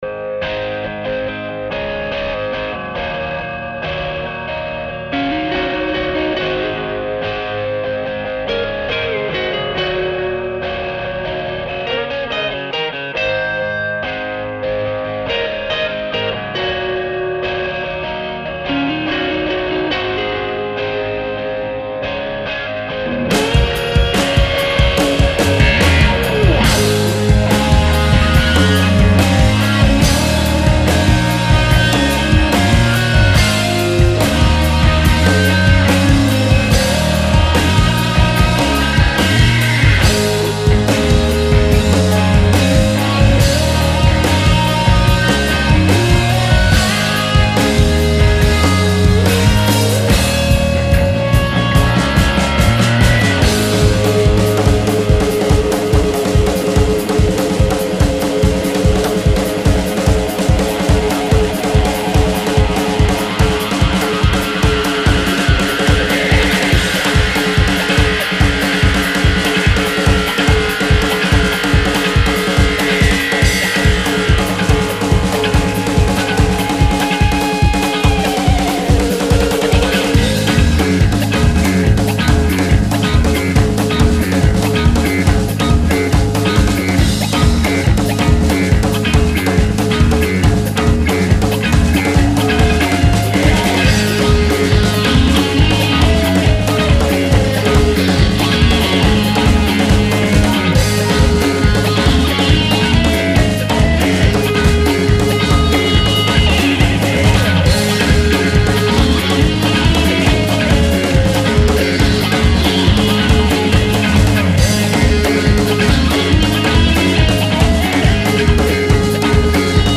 ロック・ファンク・トライバル・テクノ・ダブ・サイケデリックなど、
ロックサウンドを軸にクロスオーバーさせた楽曲達は彼らならではの感性で